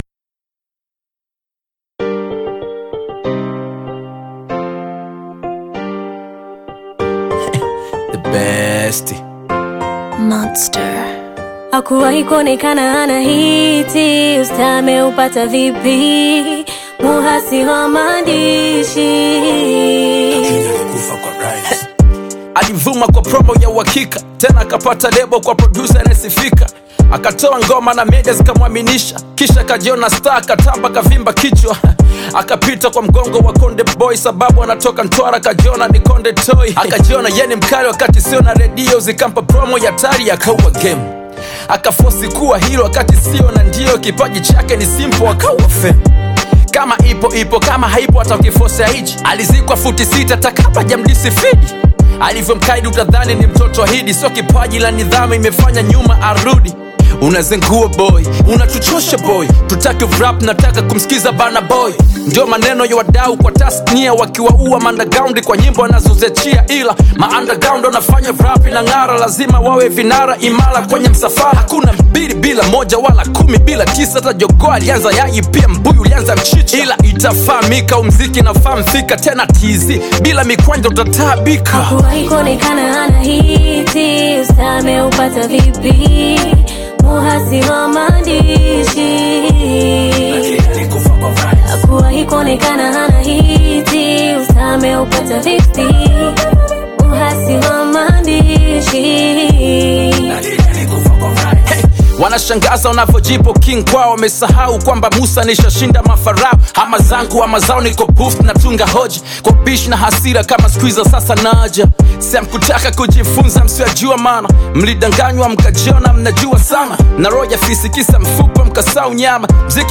Tanzanian Bongo Flava
rapper, singer, and songwriter